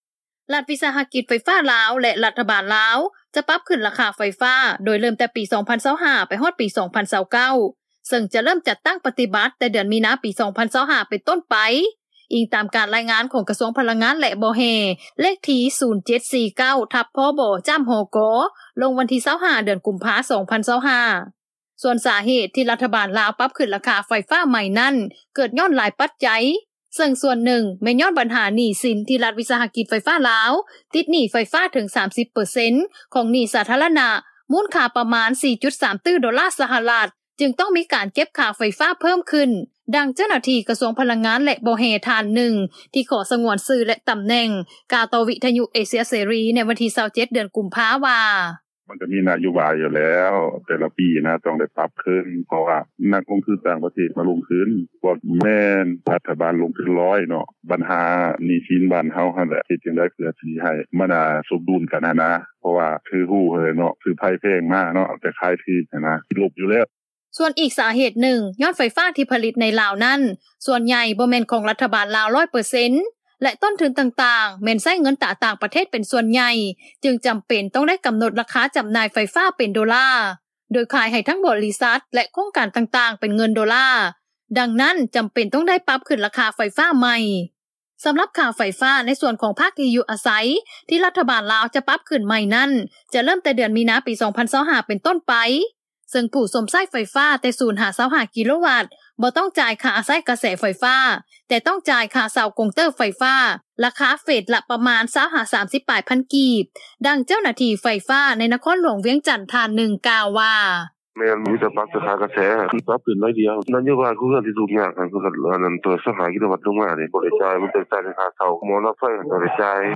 ຂະນະທີ່ ຊາວລາວ ນາງໜຶ່ງ ກໍຈົ່ມວ່າ ຖ້າຫາກລັດຖະບານລາວ ປັບຂຶ້ນຄ່າໄຟຟ້າ ໃນອັດຕາແພງຫຼາຍ ປະຊາຊົນ ກໍຈະຫຍຸ້ງຍາກ ທາງດ້ານການເງິນ ຢ່າງແນ່ນອນ ບວກກັບສະພາບເສດຖະກິດ-ການເງິນ ກໍຍັງບໍ່ທັນຟື້ນໂຕໄດ້ດີ ແຕ່ປະຊາຊົນລາວ ກໍເຮັດຫຍັງບໍ່ໄດ້ ກໍຈໍາເປັນ ຕ້ອງຈ່າຍຄ່າໄຟຟ້າຄືເກົ່າ: